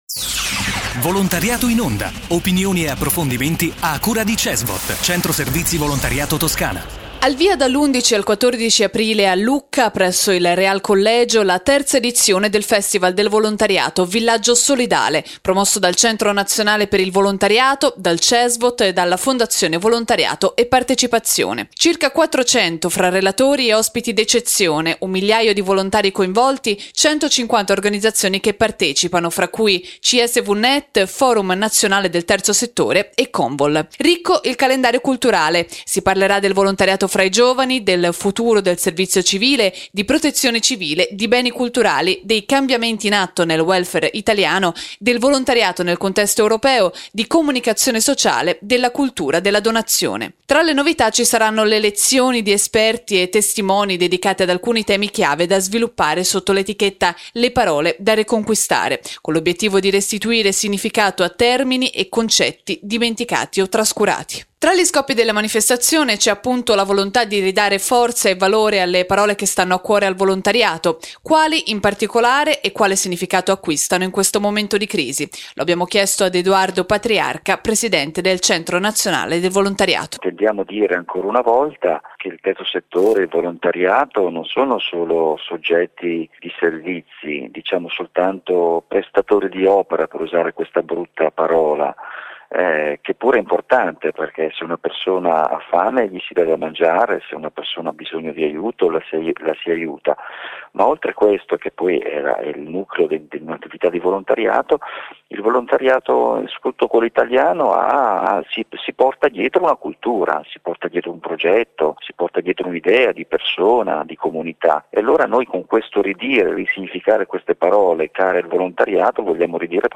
Intervista ad Edoardo Patriarca, presidente del Centro nazionale per il volontariato